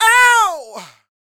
C-YELL 3401.wav